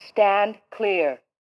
Звуки дефибриллятора
Device alert in English (Stand clear)